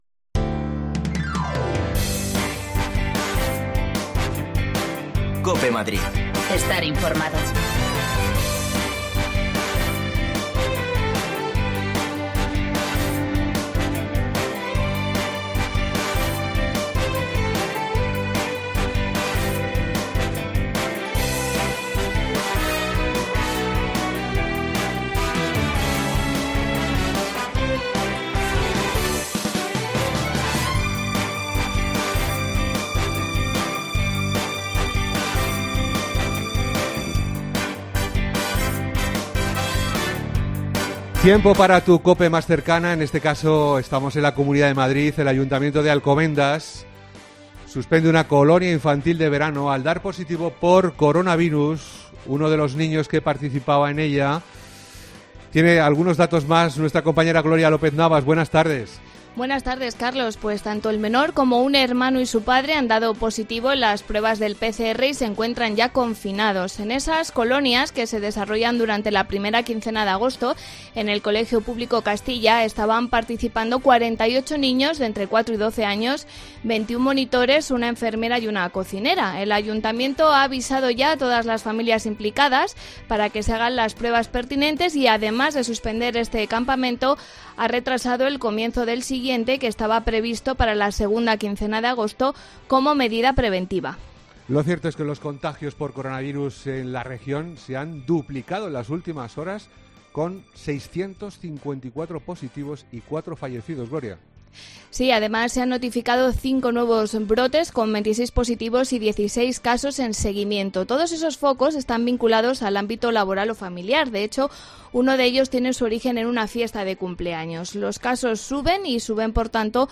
Madrid apuesta por iniciar el curso con el escenario 1 de relativa normalidad pero se muestra partidaria de cambiarlo si las condiciones varian. Hablamos con padres y profesores.